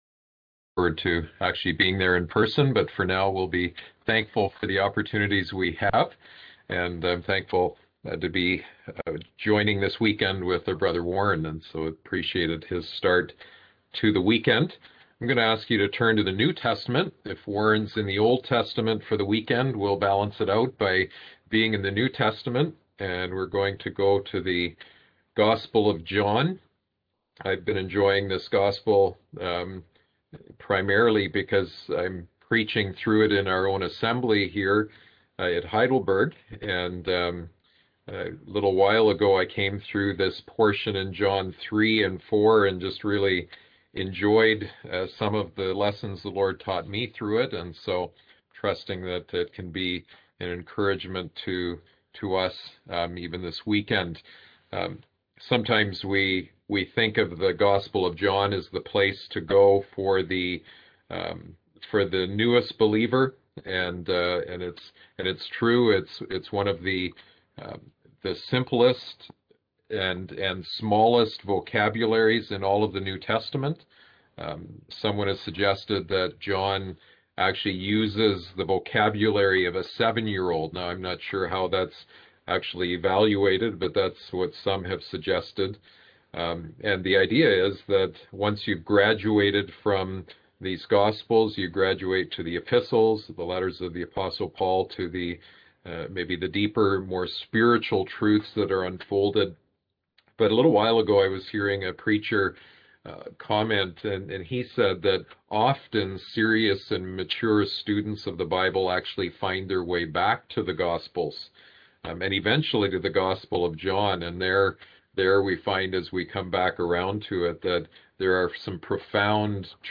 Series: Easter Conference Passage: John 3 Service Type: Seminar